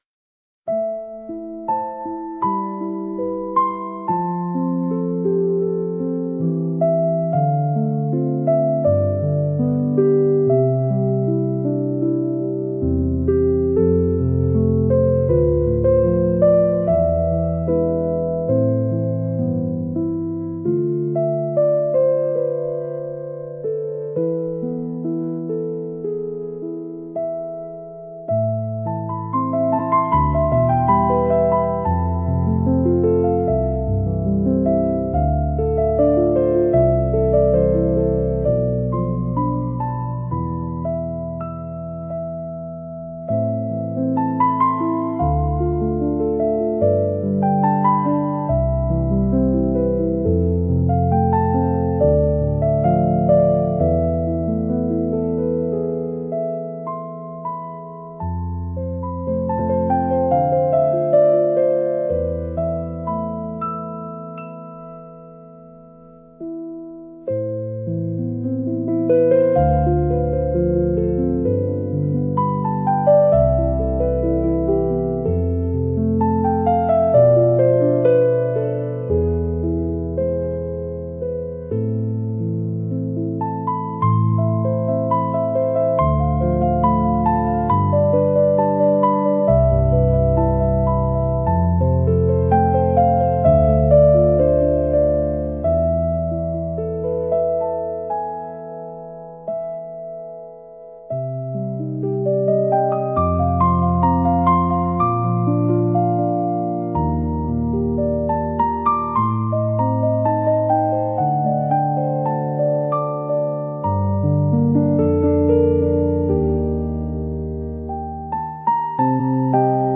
If you are curious what a commercial service such as Suno will create from the prompt “music in the style of Bach's Aria from the Goldberg Variations”.
Here is an example, where no learning anything is necessary, just copy the above prompt in the create box and check the instrumental option: